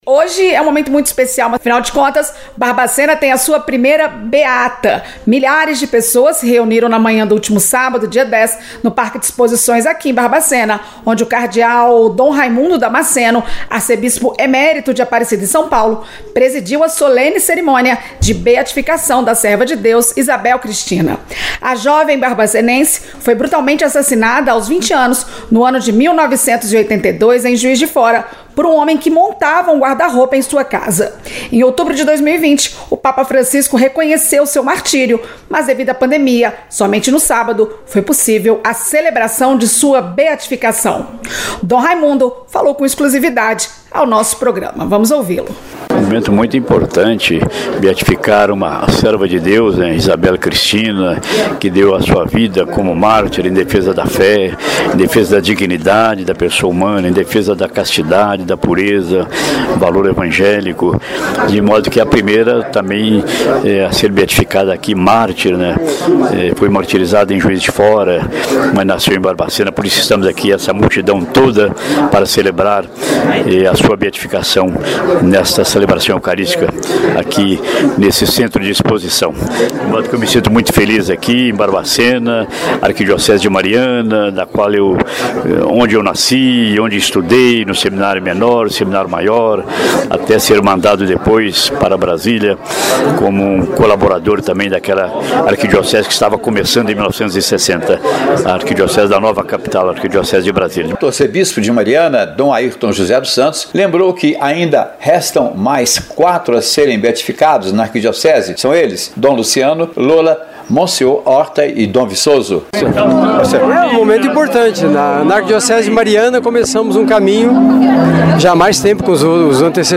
O Itatiaia Entrevista desta edição especial traz a cobertura da cerimônia de beatificação de Isabel Cristina.
Confira um trecho da cobertura realizada pela Rádio Correio da Serra, afiliada da Rede Itasat em Barbacena.